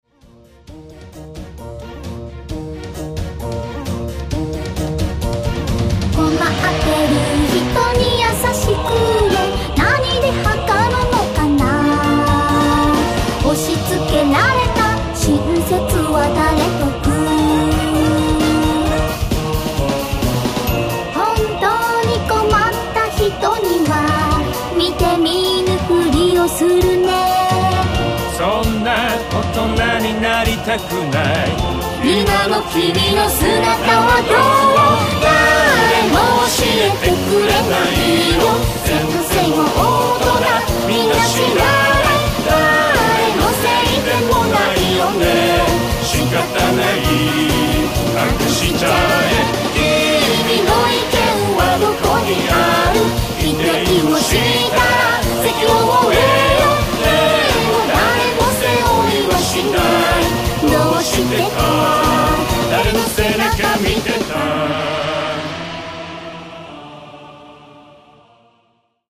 歌唱全8曲オリジナル
ダークファンタジー物語CD
※マスタリング前の音源です。
音質はCDのものより劣化しております。